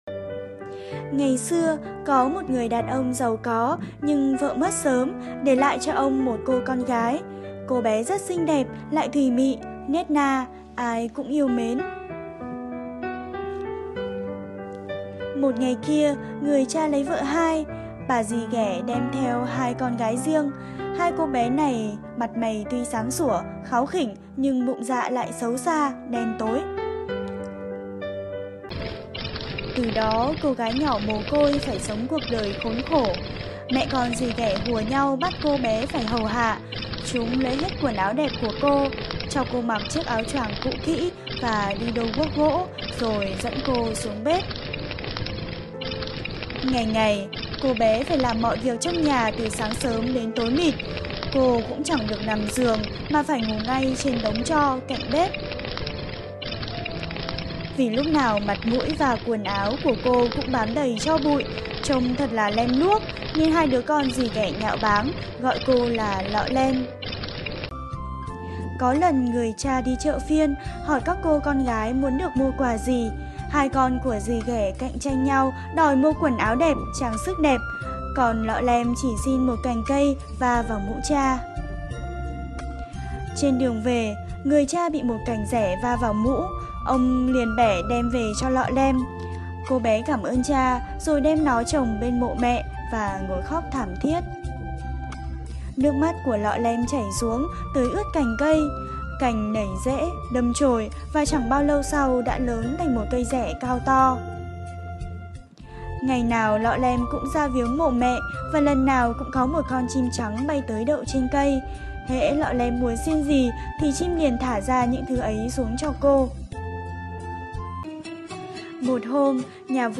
Sách nói | Cô bé lọ lem